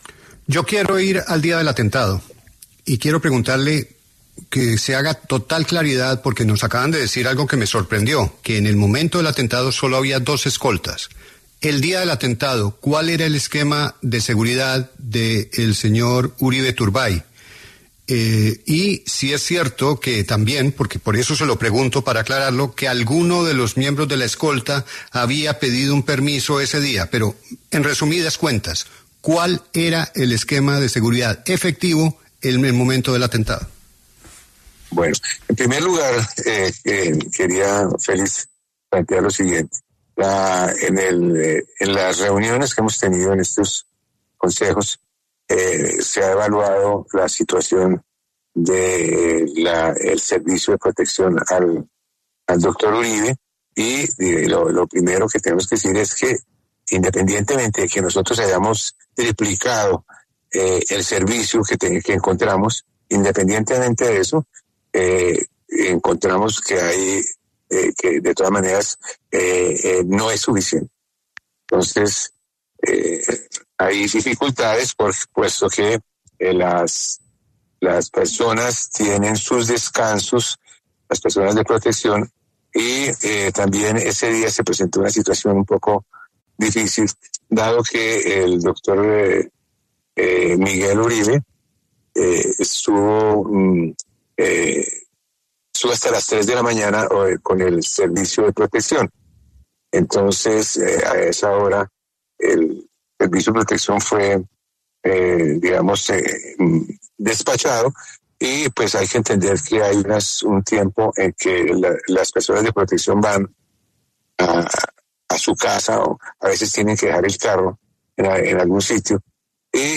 En conversación con La W, el director de la Unidad Nacional de Protección, Augusto Rodríguez, compartió varios detalles sobre cómo estaba compuesto el equipo de seguridad que acompañó al exsenador Miguel Uribe Turbay el día del atentado en su contra.